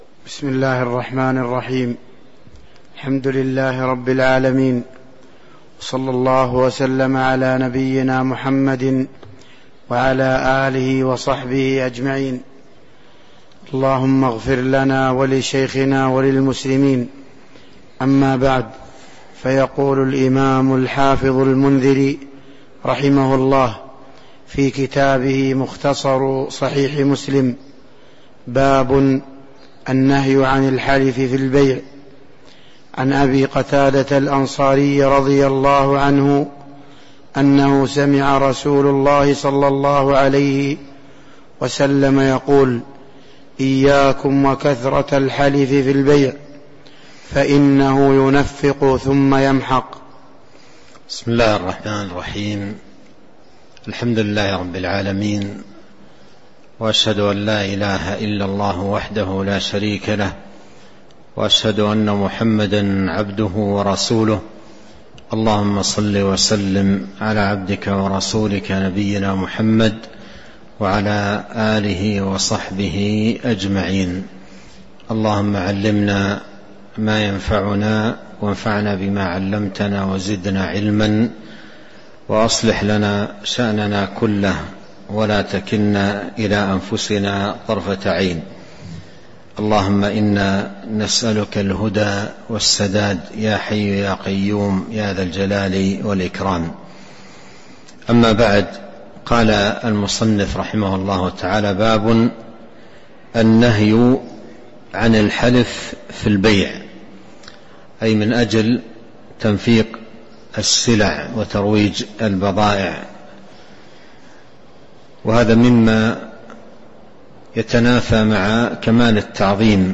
تاريخ النشر ١٠ صفر ١٤٤٣ هـ المكان: المسجد النبوي الشيخ